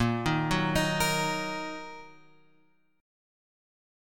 A#sus4#5 chord {6 6 8 8 7 6} chord